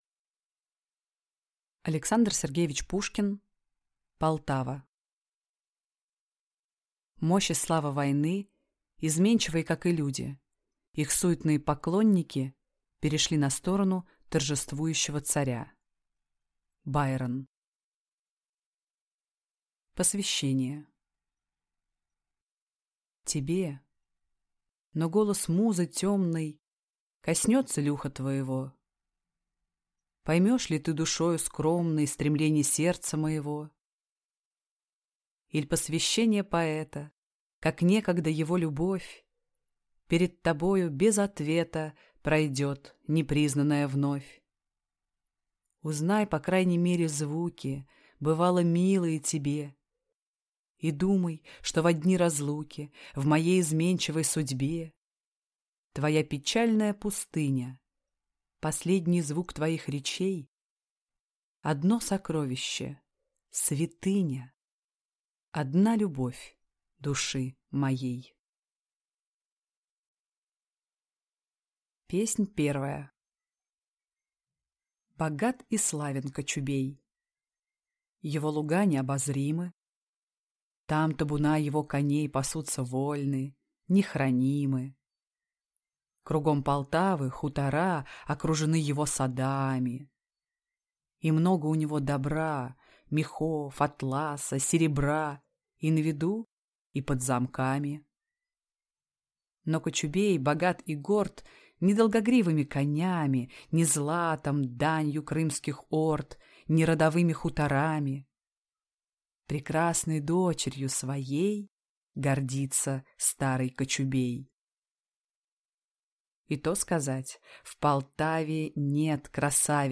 Аудиокнига Полтава | Библиотека аудиокниг